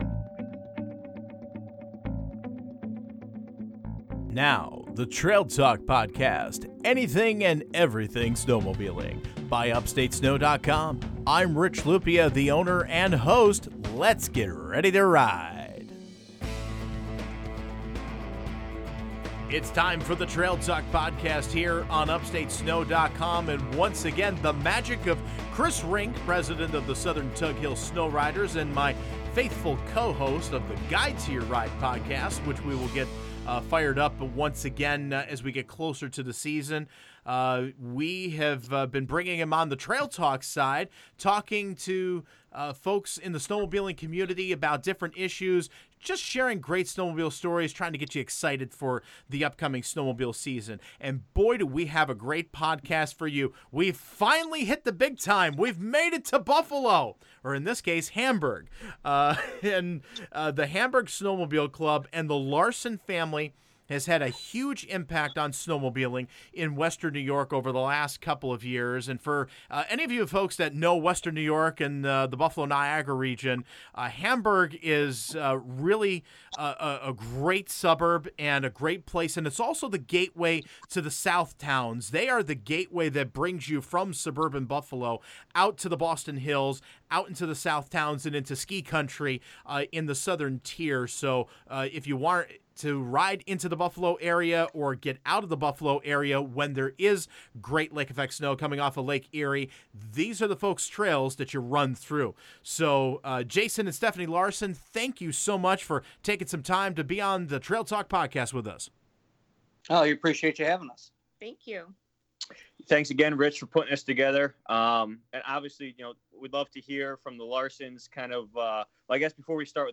This is a great interview with a great snowmobile club!